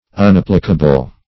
Unapplicable \Un*ap"pli*ca*ble\, a.